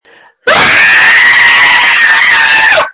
• When you call, we record you making sounds. Hopefully screaming.
• This website is an archive of the recordings we received from hundreds of thousands of callers.